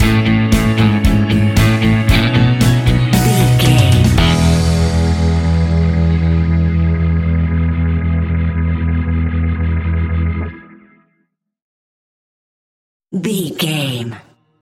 Epic / Action
Fast paced
Ionian/Major
pop rock
indie pop
fun
energetic
uplifting
motivational
acoustic guitars
drums
bass guitar
electric guitar
piano
organ